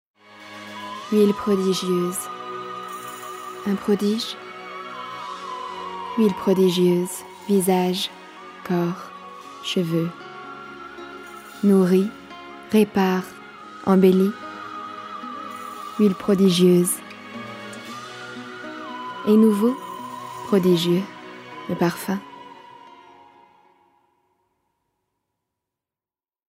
Publicité Huile Prodigieuse